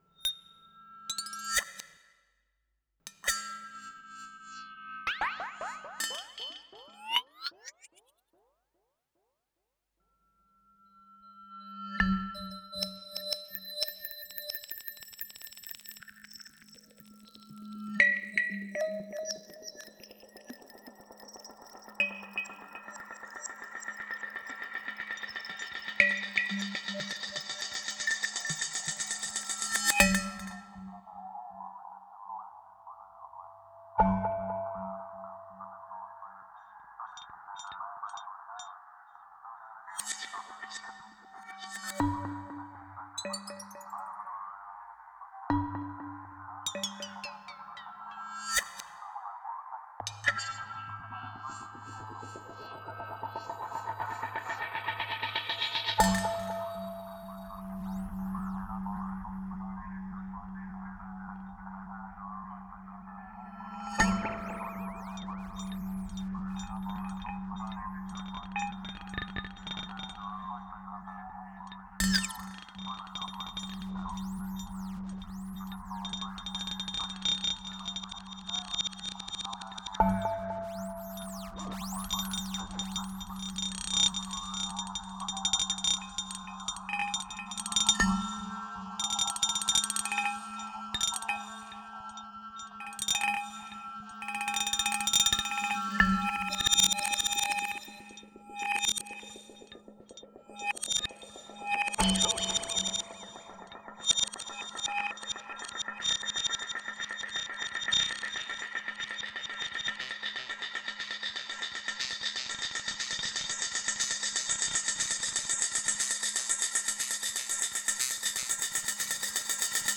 Etude (2021) is a Sonic Sculpture, based on recordings of different bottles being struck with a variety of metal items in order to create a plethora of different sounds, for further treatment and development.